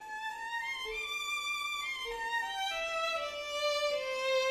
Après la double exposition du thème principal survient, au bois, un thème de transition[6].